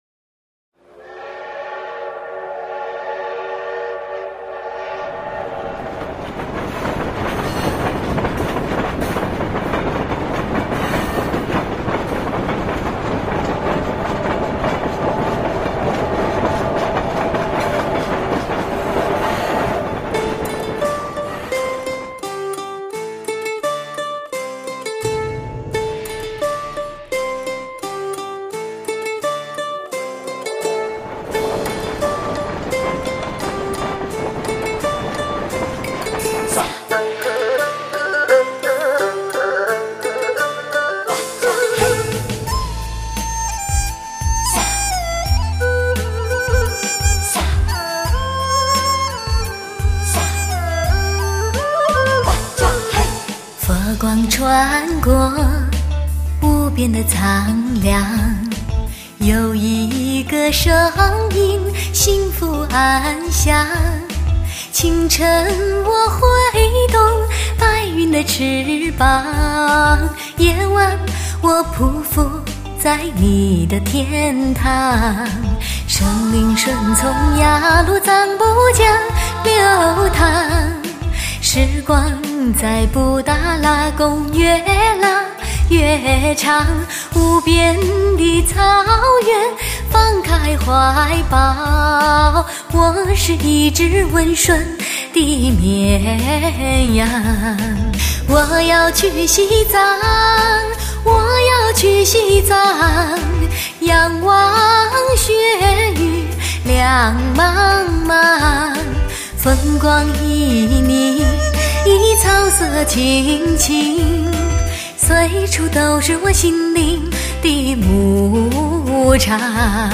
美国震撼性DTS6.1环绕声 最逼真还原典范女声演唱场；